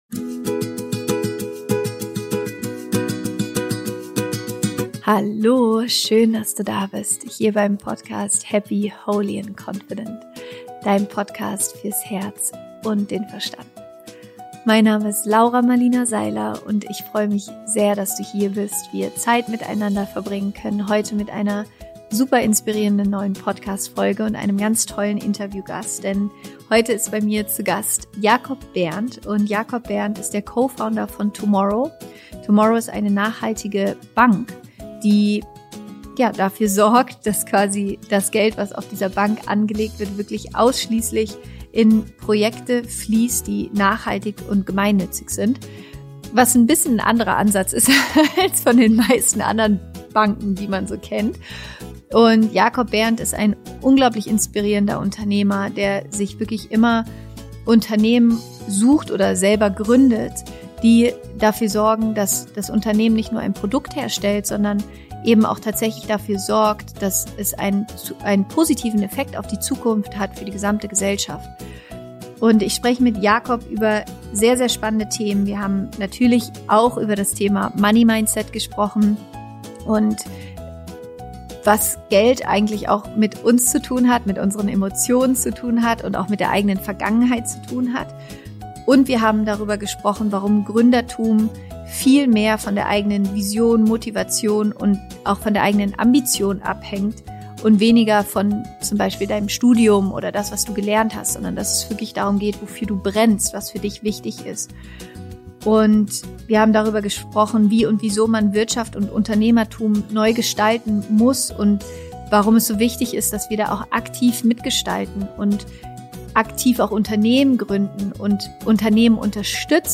Viel Spaß bei diesem inspirierenden Interview!